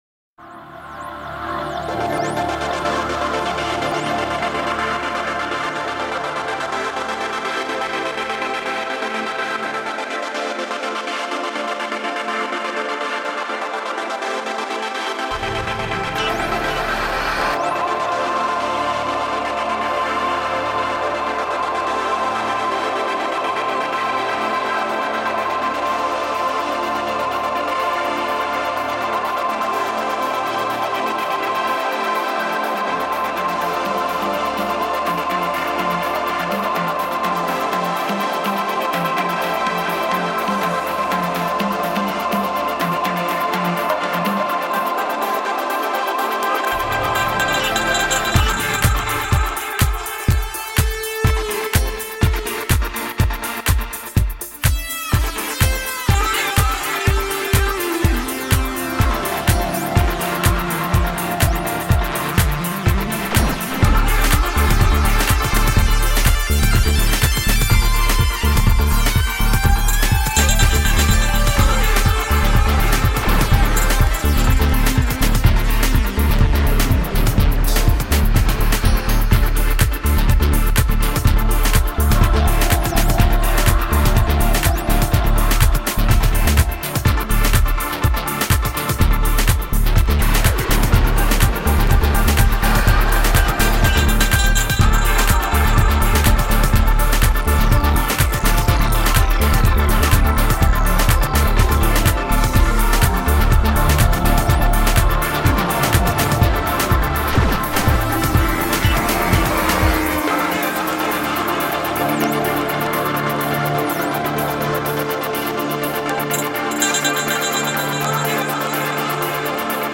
Energetic and organic clubhouse.
Tagged as: Electronica, Other